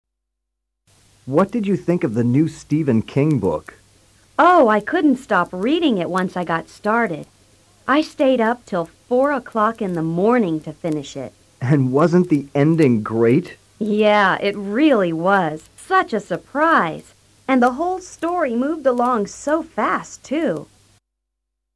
Cuatro personas opinan sobre libros y cine.